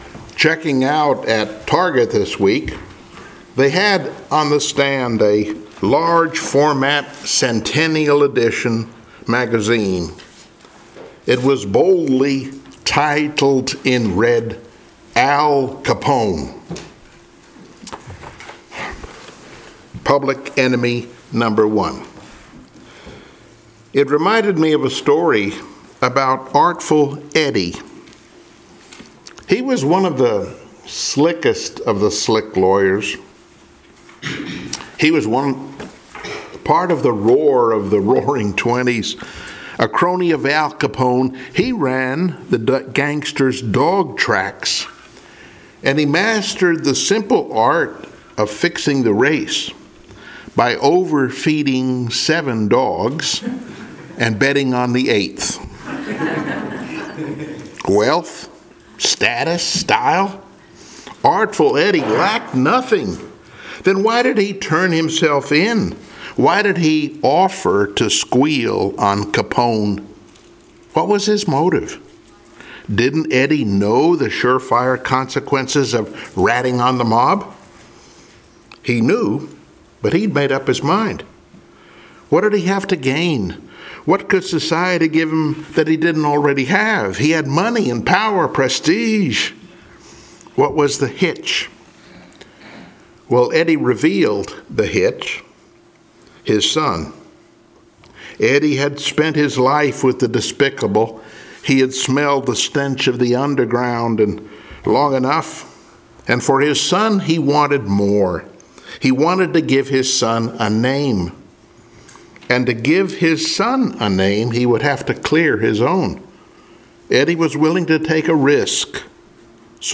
Passage: Matthew 26:6-13 Service Type: Sunday Morning Worship